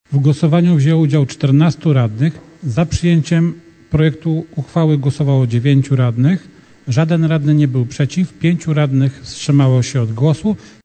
Podczas ostatniej sesji Rady Miejskiej w Myśliborzu odbyła się debata między radnymi dotycząca przychodów i wydatków gminy w przyszłym roku.
Wyniki głosowania radnych w sprawie uchwalenia budżetu Gminy Myślibórz na 2024 rok przedstawił przewodniczący rady Marek Antczak